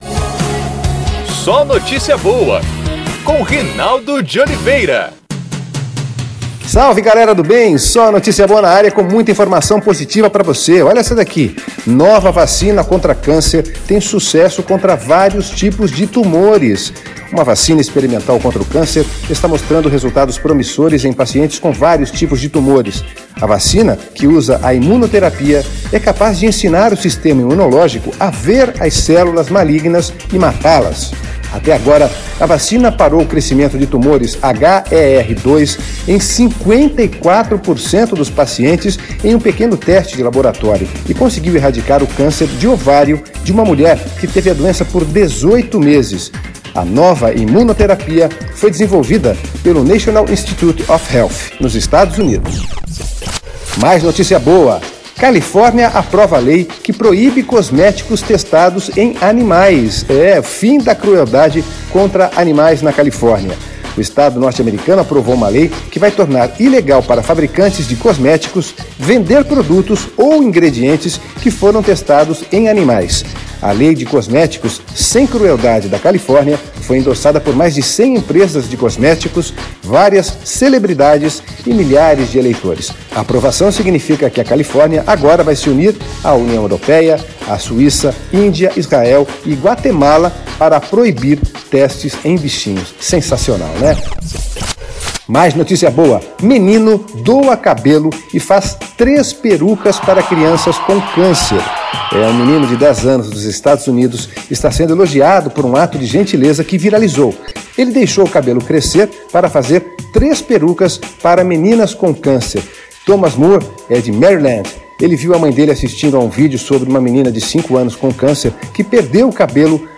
É o podcast SóNotíciaBoa, nosso programa de rádio.